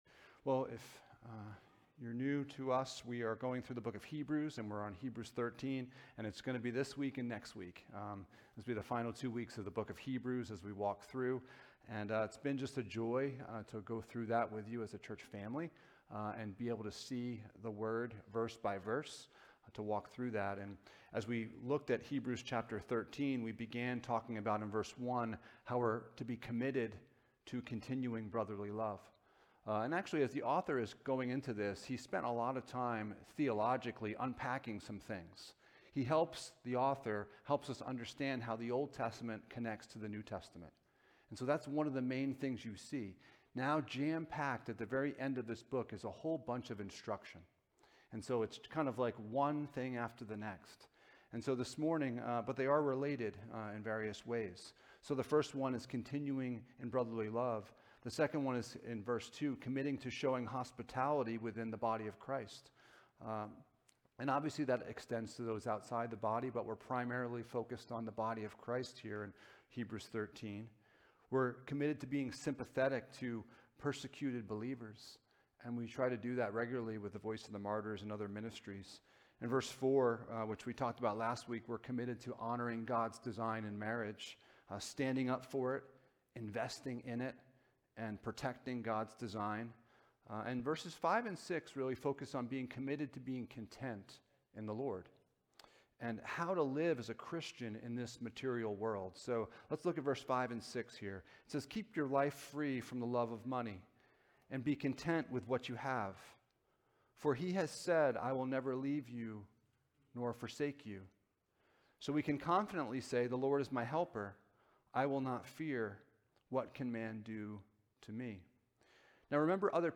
Jesus is Better Passage: Hebrews 13: 5-19 Service Type: Sunday Morning « What happened to God’s design for Marriage?